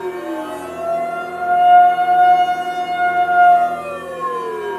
alarm_siren_loop_01.wav